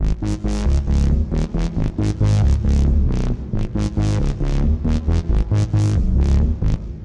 奇怪的低音合成器
描述：只是在Massive上瞎折腾，做了这个低音合成器的声音。可以用在滑稽或电子的轨道上，或者其他什么地方。音符是Gm的。
Tag: 136 bpm Electro Loops Bass Synth Loops 1.19 MB wav Key : G